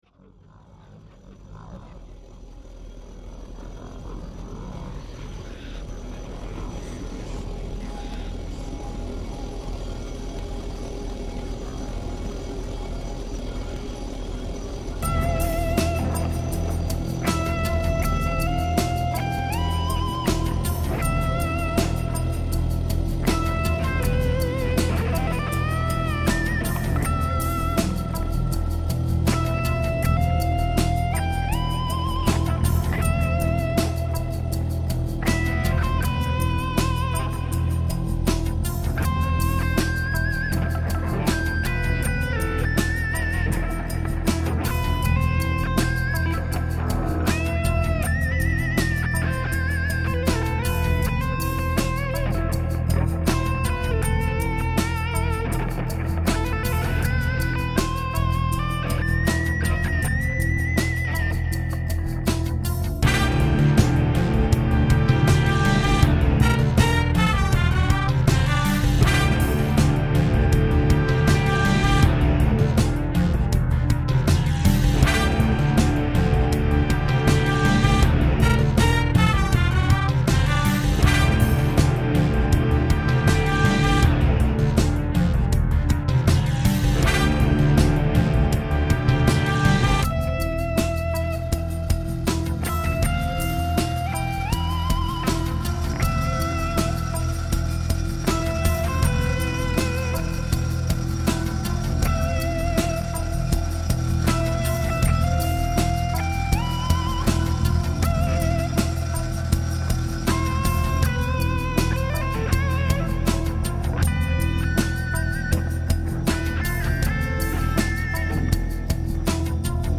I’m a guitar player and play electric guitar and bass.
Back in 2008 I had a music revival and made some recordings of music at home with the use of my computer.